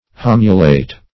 Search Result for " hamulate" : The Collaborative International Dictionary of English v.0.48: Hamulate \Ham"u*late\ (-l[asl]t), a. Furnished with a small hook; hook-shaped.